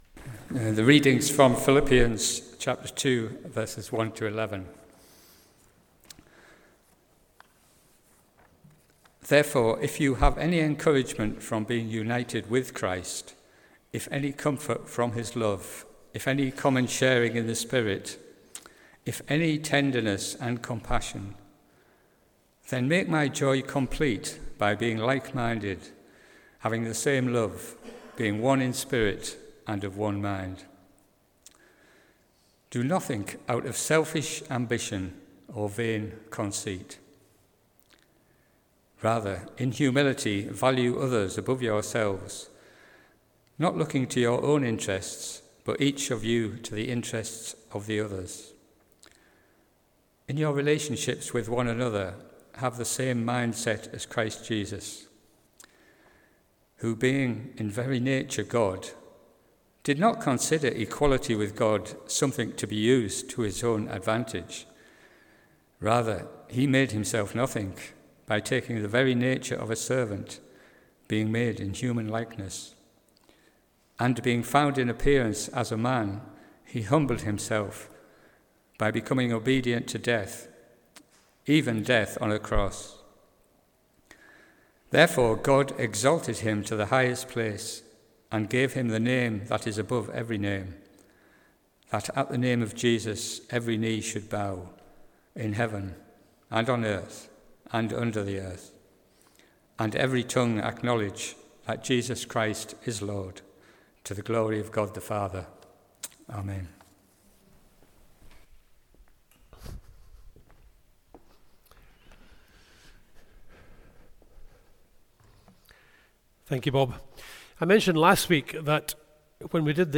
Passage: Philippians 2:1-11 Service Type: Sunday Morning « Paul’s new description of the glorious light of the Good News The short hop from loving introspection to intended murder.